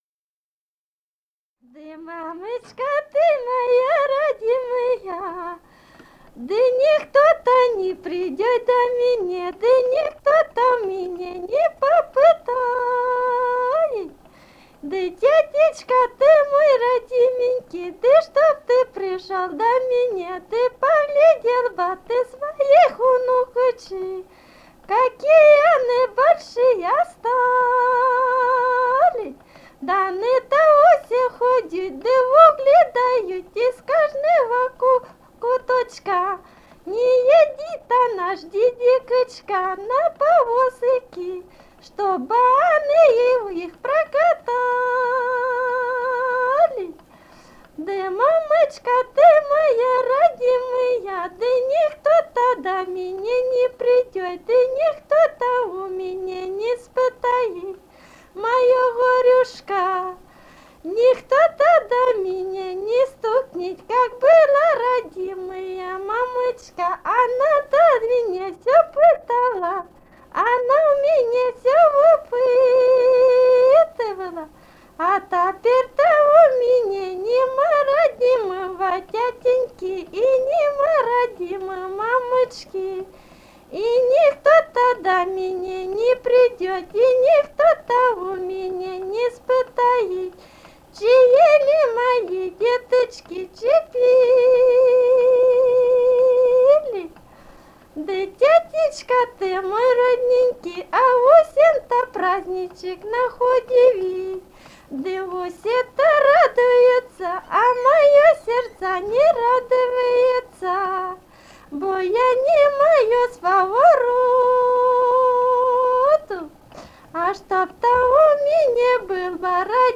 Румыния, с. Переправа, 1967 г. И0974-12